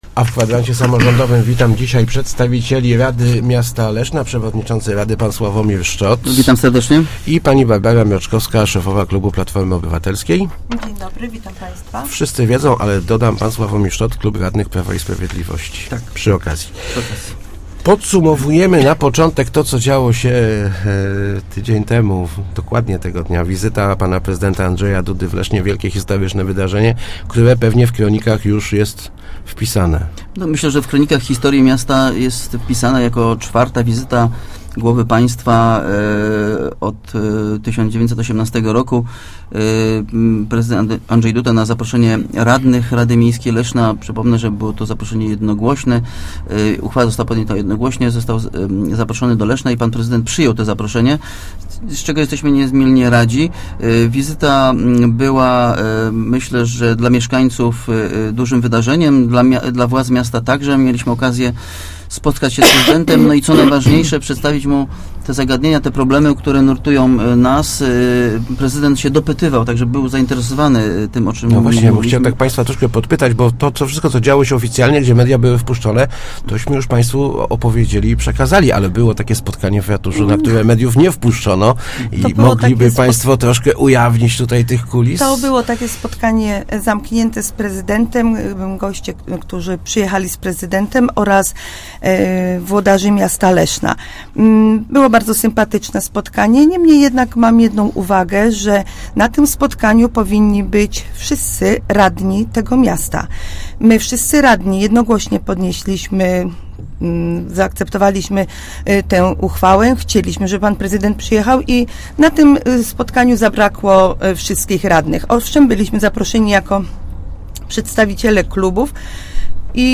Co do prezydenckiej propozycji podzia�u wolnych �rodk�w radni w Kwadransie Samorz�dowym przyznali, �e uwzgl�dnia on wiele postulat�w klubowych.